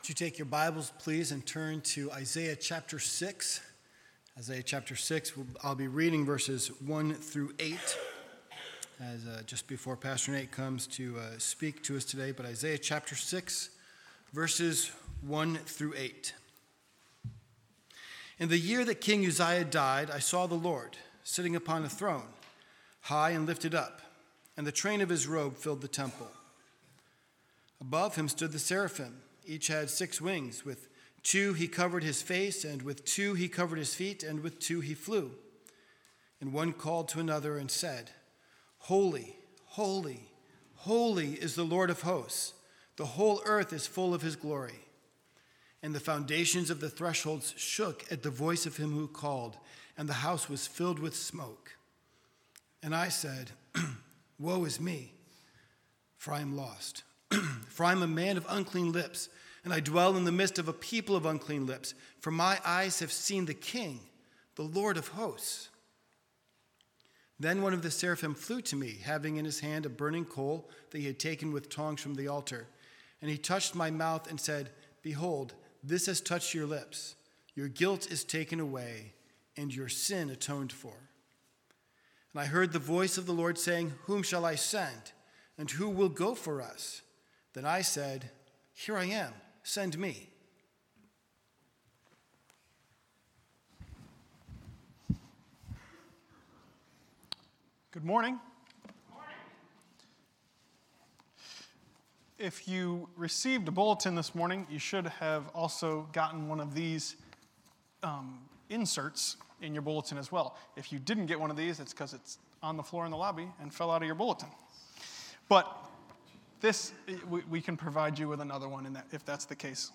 Bible Text: Isaiah 6:1-8 | Preacher